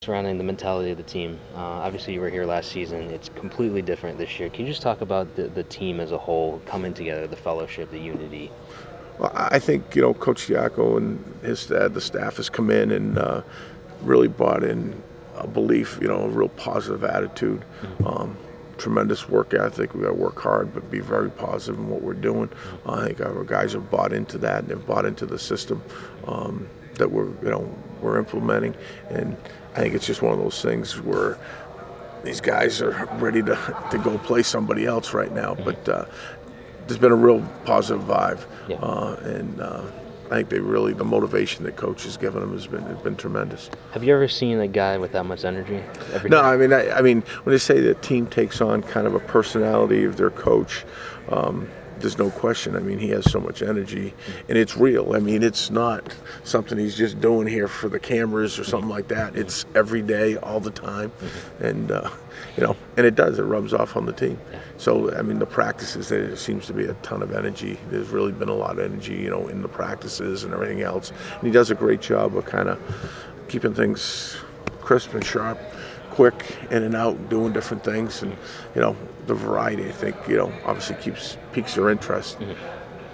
In Depth Interview